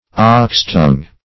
ox-tongue - definition of ox-tongue - synonyms, pronunciation, spelling from Free Dictionary
oxtongue \ox"tongue`\, ox-tongue \ox"-tongue`\, n. (Bot.)